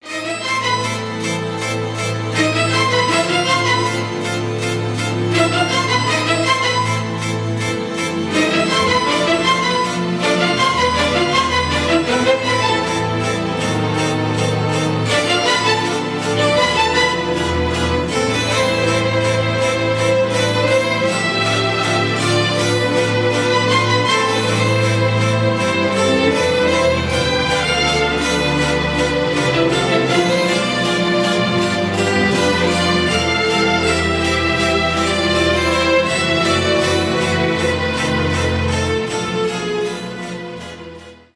инструментальные
без слов